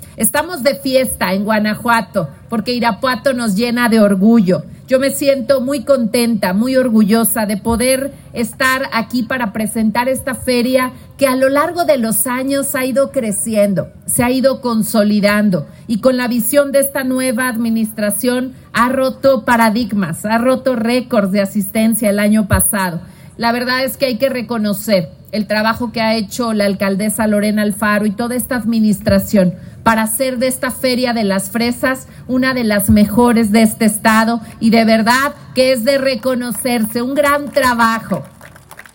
Libia Dennise García Muñoz Ledo, Gobernador del Estado de Guanajuato